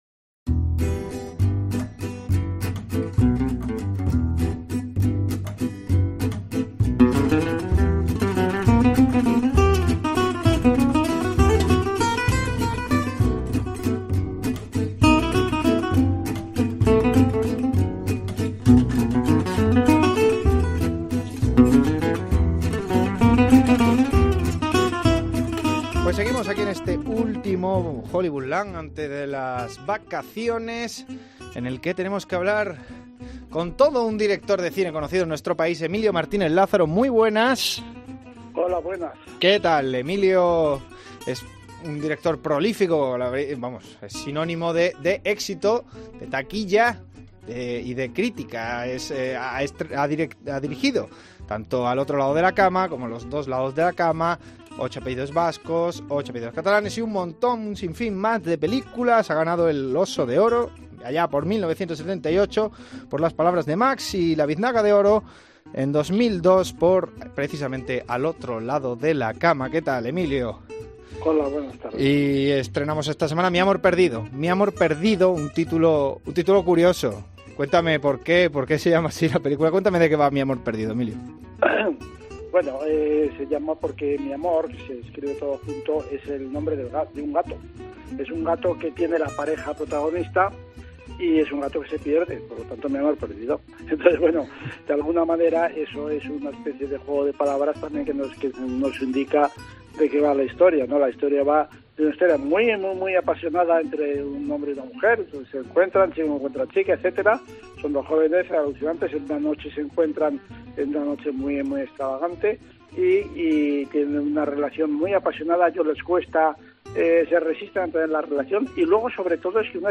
Entrevista Emilio Martínez-Lázaro